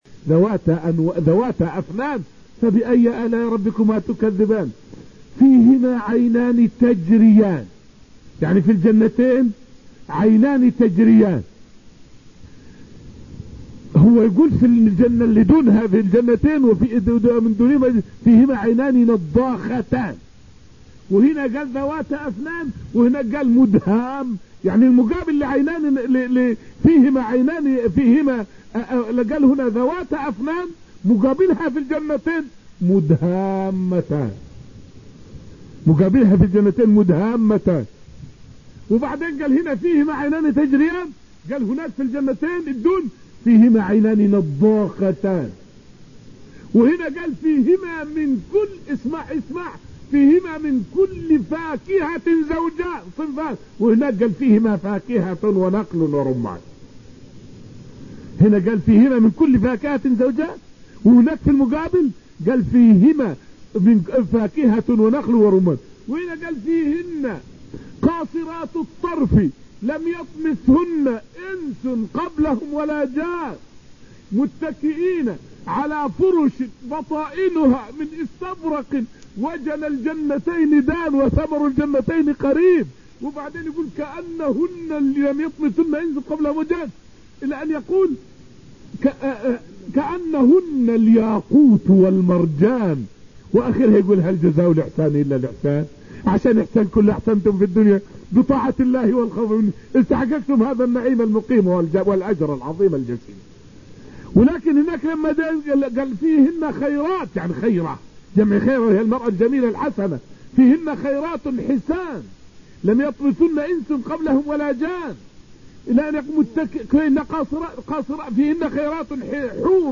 فائدة من الدرس الحادي عشر من دروس تفسير سورة الرحمن والتي ألقيت في المسجد النبوي الشريف حول مقارنة بين الجنتين في قوله تعالى {ولمن خاف مقام ربه جنتان} والجنتين في قوله {ومن دونهما جنتان}.